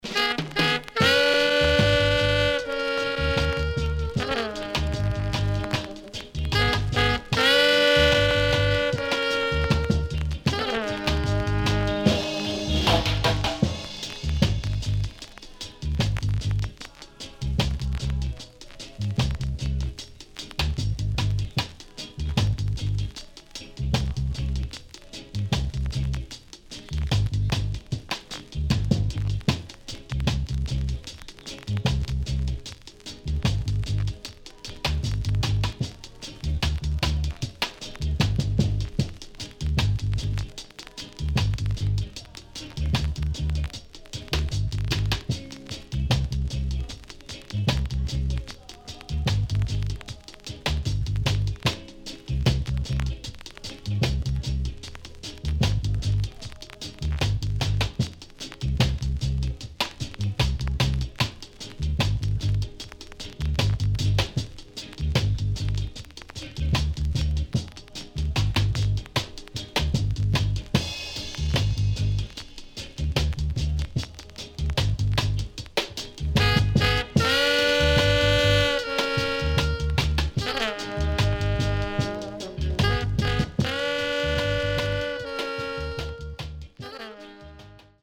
HOME > REGGAE / ROOTS  >  KILLER & DEEP  >  70’s DEEJAY
CONDITION SIDE A:VG(OK)
SIDE A:プレス起因のノイズがあり、少しパチノイズ入ります。